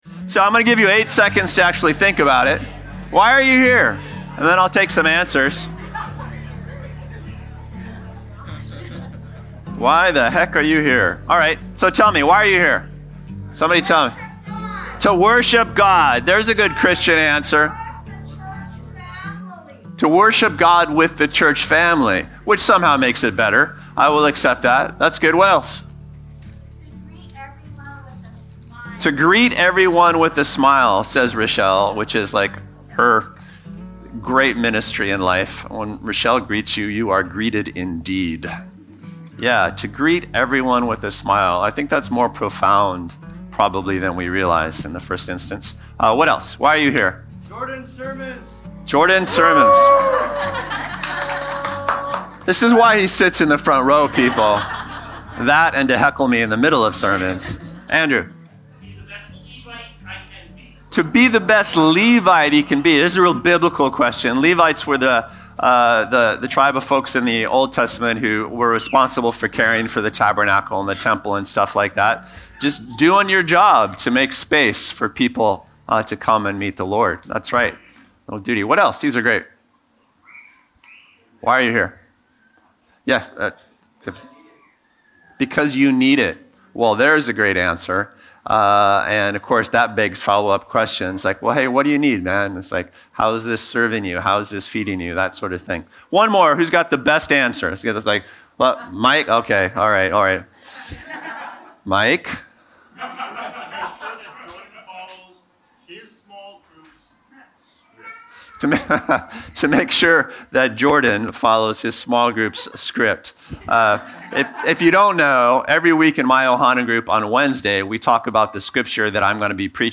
Podcasts of Bluewater Mission sermons, updated weekly.